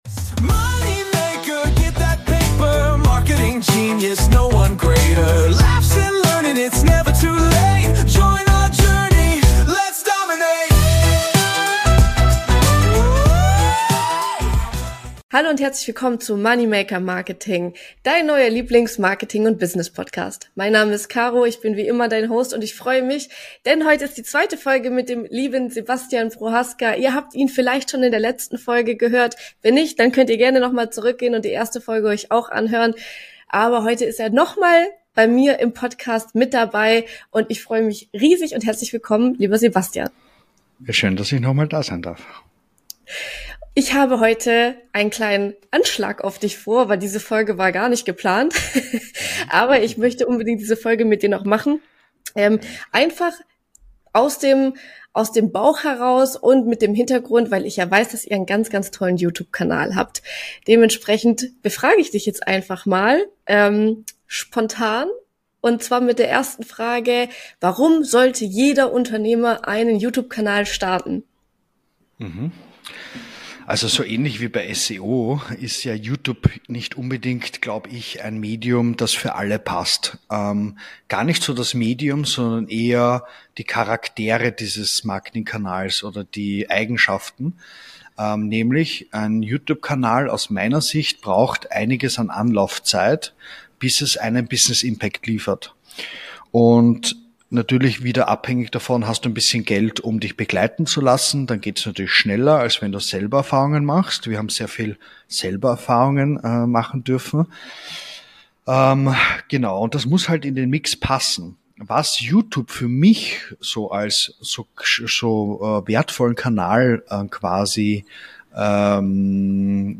MMM 8: YOUTUBE ALS MARKETINGMASCHINE! INTERVIEW